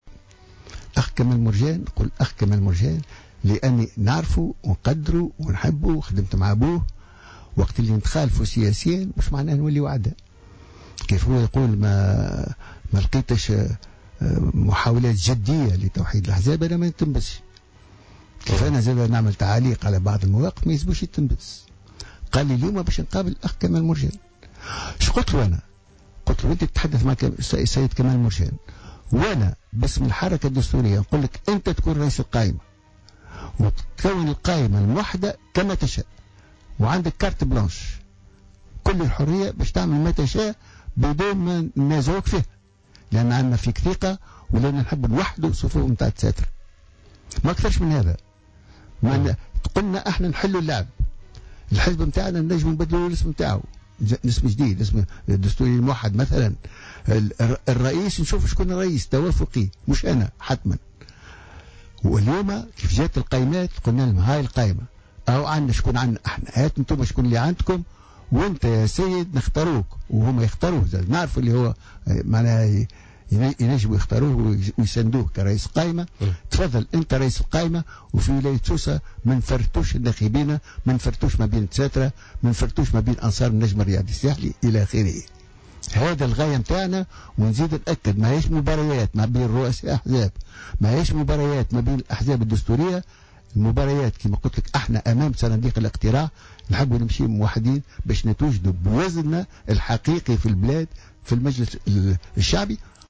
قال رئيس الحركة الوطنية الدستورية, حامد القروي, ضيف حصة بوليتيكا على جوهرة أف أم اليوم الإثنين إن أولوية حزبه في المرحلة المقبلة هي استمالة أكثر ما يمكن من الدساترة ولم شملهم وخاصة منهم الدساترة المشتتين وغير المنضوين في أحزاب أخرى مؤكدا أن باب الحركة الدستورية مفتوح أمام جميع الأحزاب الدستورية.